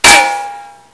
pain100_1.wav